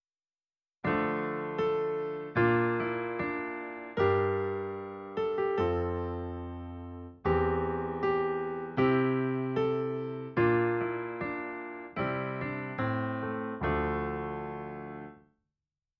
After I finished talking to the client over the phone, within ten minutes or so I worked out a melody and arranged it for piano.
Main Theme excerpt (piano):